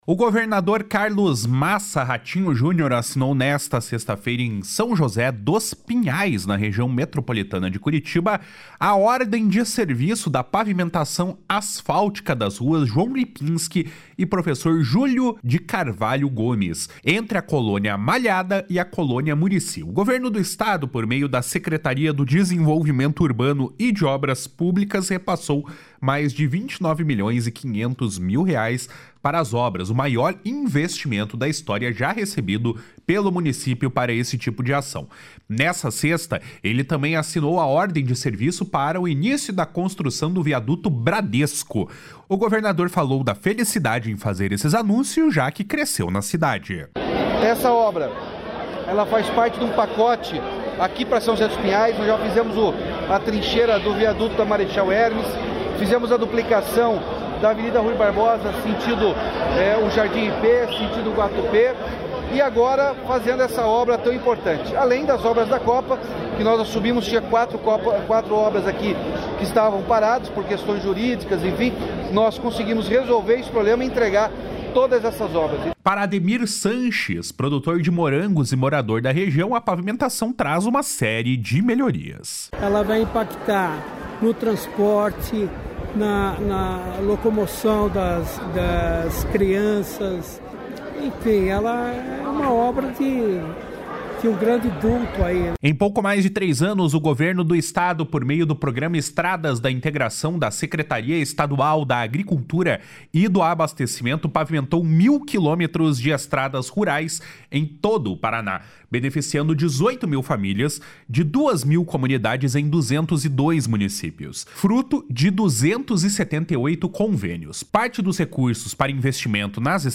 O governador falou da felicidade em fazer esses anúncios, já que cresceu na cidade.